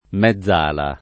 mezz’ala
mezz’ala [ m Hzz # la ] o mezzala [id.] s. f. (sport.)